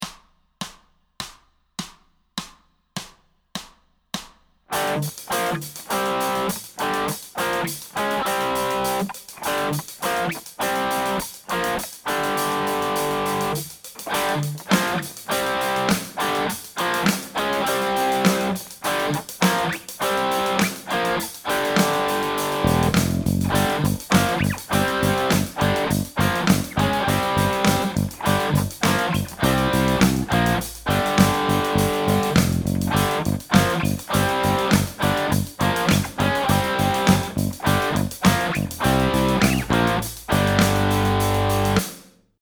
Kleines Tutorial - Dein erstes Riff auf der E-Gitarre
Es wird nur mit zwei Fingern gegriffen: